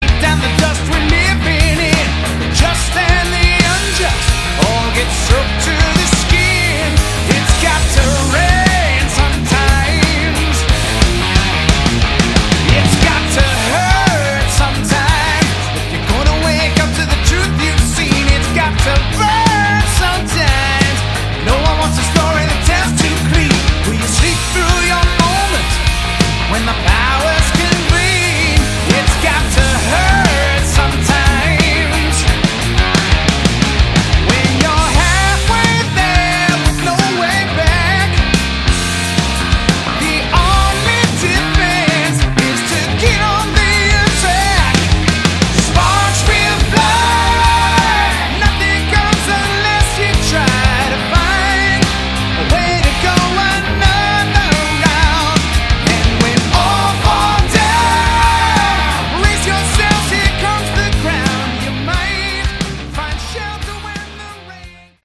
Category: Hard Rock
drums, vocals
keyboards, vocals
guitar, vocals
bass, vocals